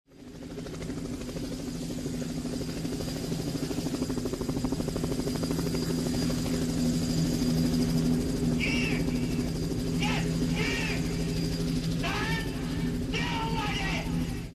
pad